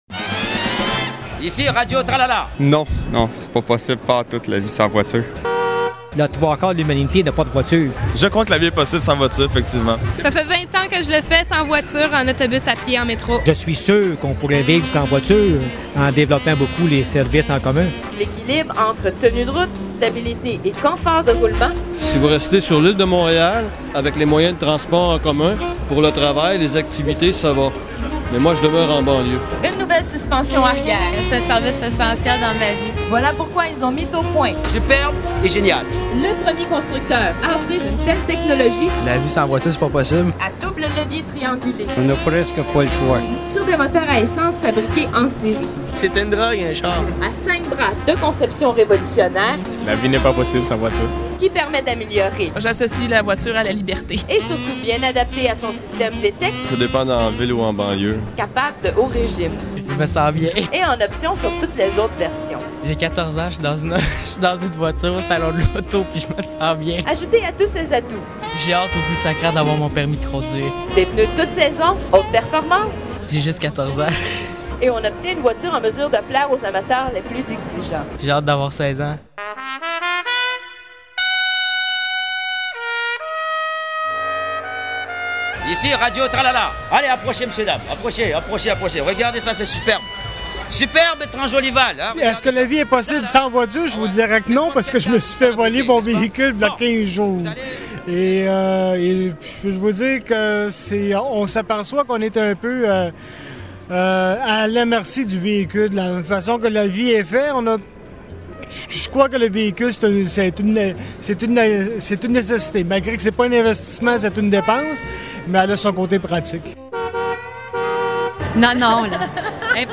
Reportage
C�est dans un Salon de l�auto à Montréal que je me suis rendu pour poser cette question à une trentaine de jeunes.